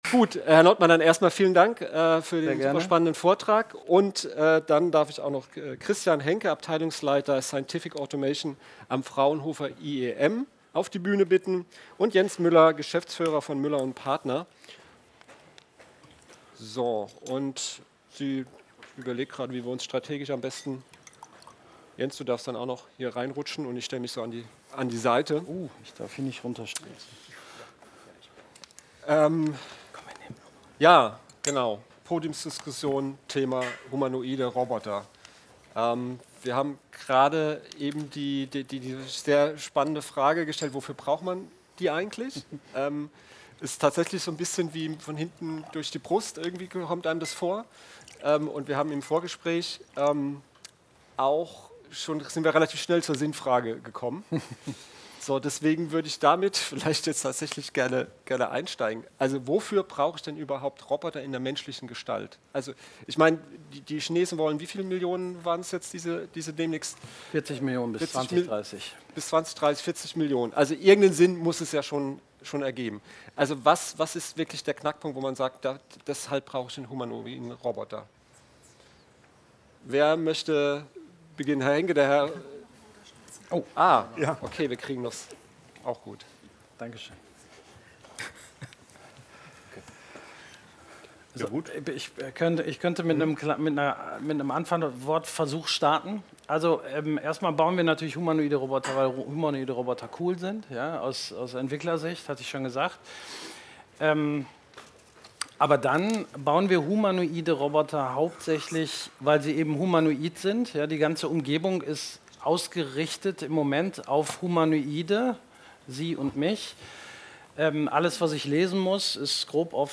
14. Robotics Kongress - Technology Academy Group
Podiumsdiskussion: Humanoide Roboter – Einsatz in der Industrie?